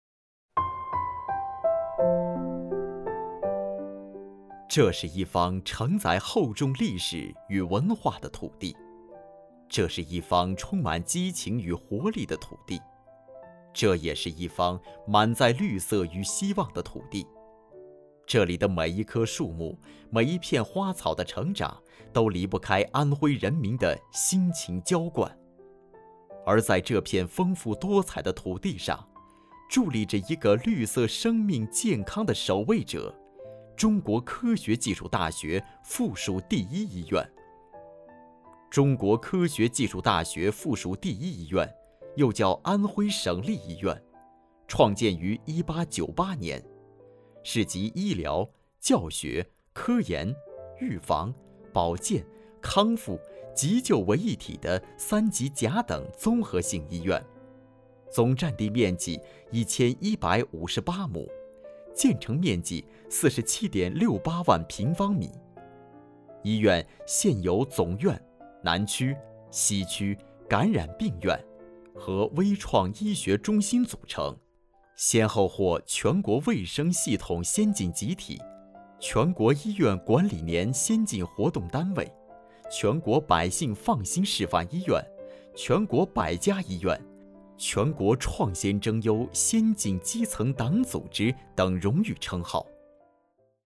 旁白-龙应台—目送—深情 讲述 沉稳|低沉|娓娓道来|素人